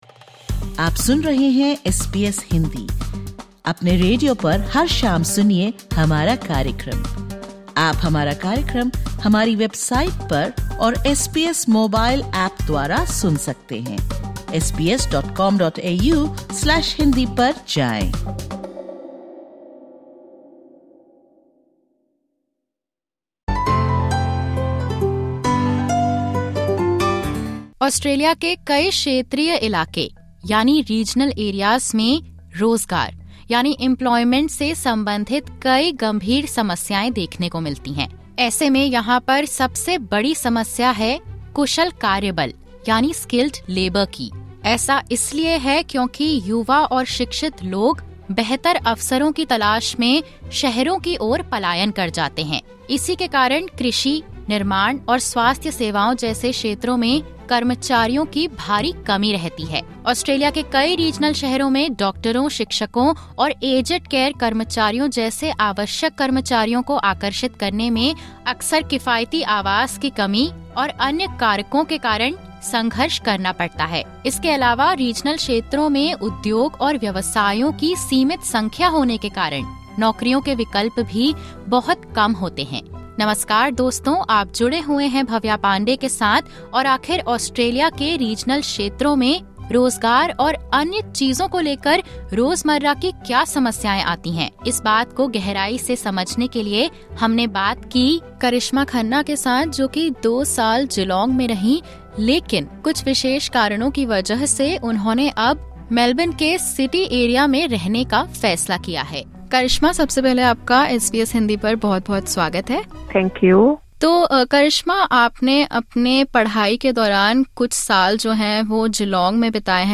( Disclaimer: The views/opinions expressed in this interview are the personal views of the individual.